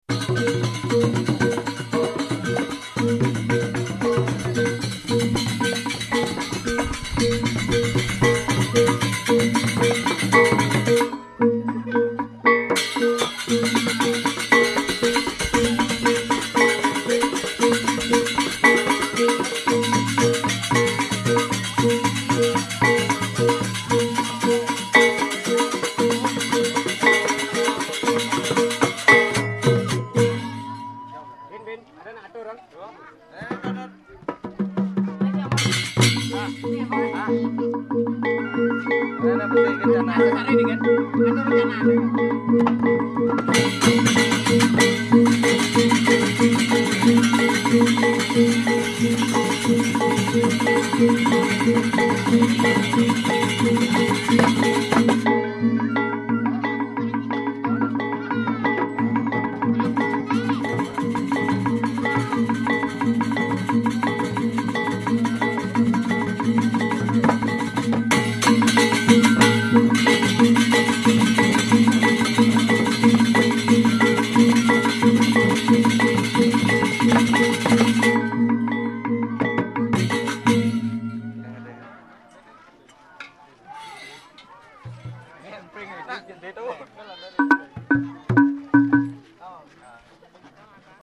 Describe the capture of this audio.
original recordings made on Digital Audio Tape using a pair of Sony DMR-V7 headphones as the microphone